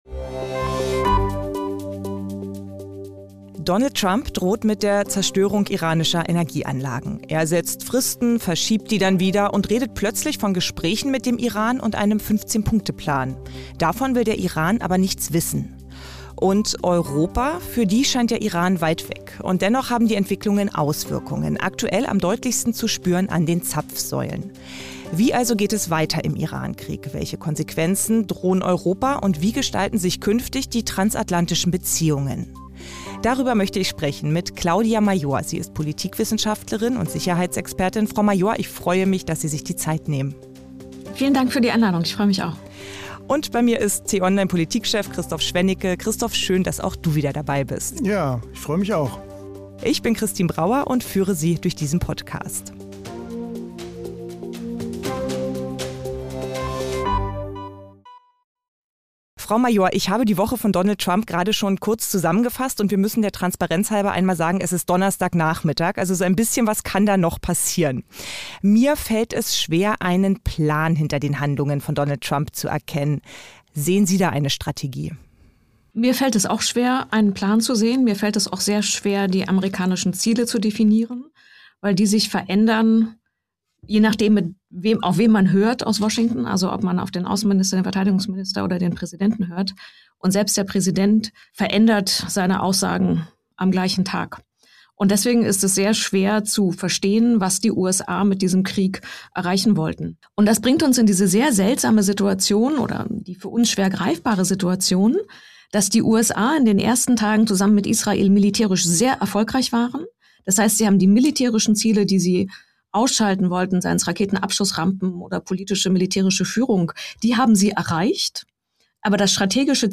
Den „Tagesanbruch“-Podcast gibt es immer montags bis samstags gegen 6 Uhr zum Start in den Tag – am Wochenende in einer längeren Diskussion.